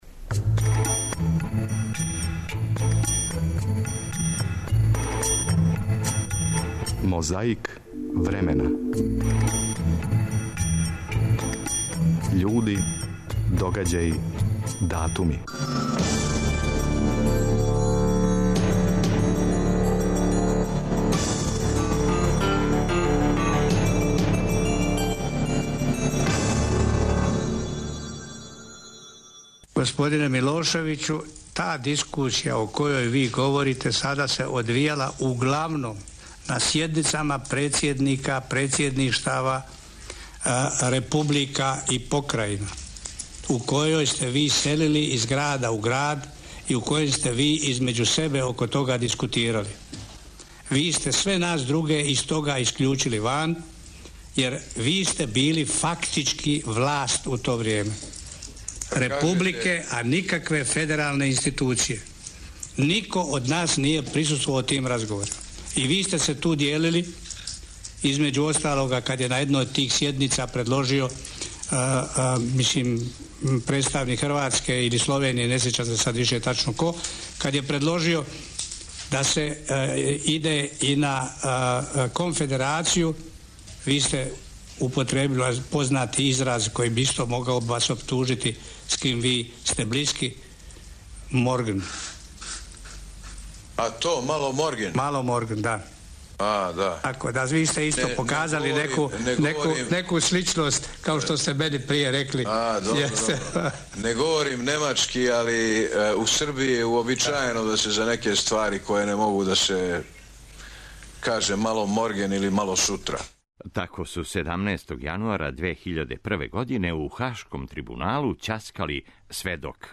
Речник српско-хрватског књижевног и народног језика, Књига V. Чућемо заклетве двојице америчких председника. Прво, Двајт Ајзенхауер, 20. јануара 1953. године.